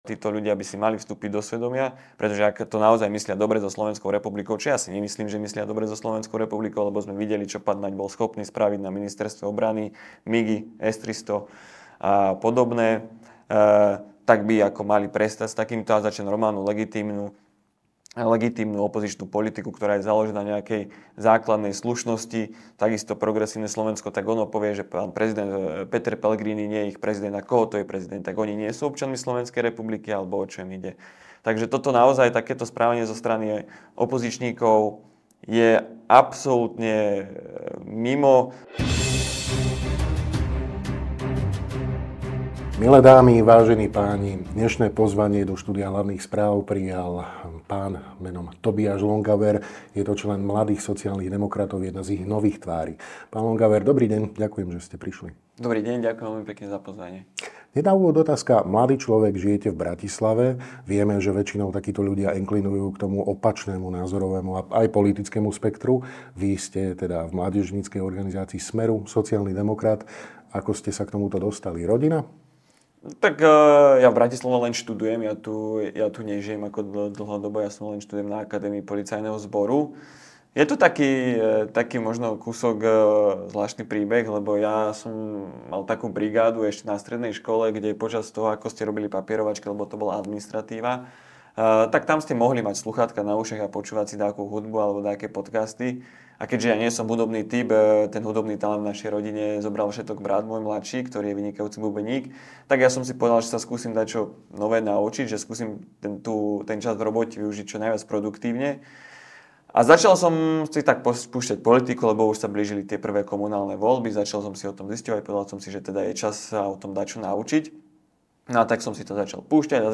Viac vo videorozhovore.